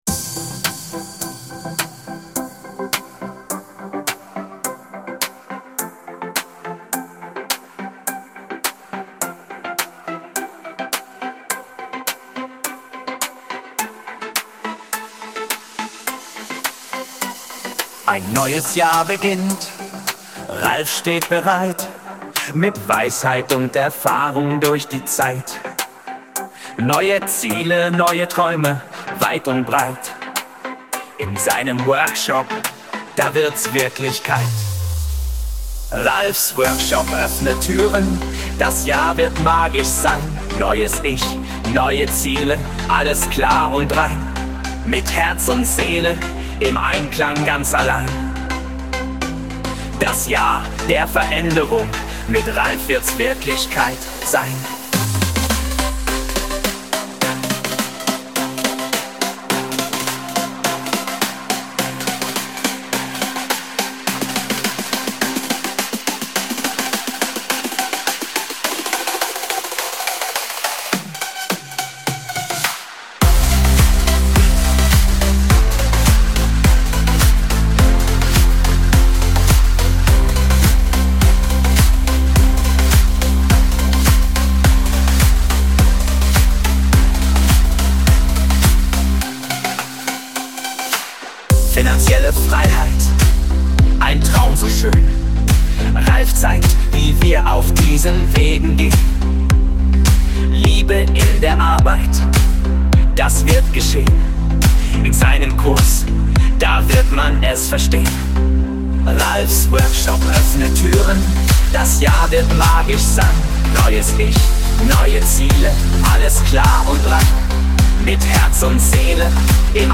KI-Musik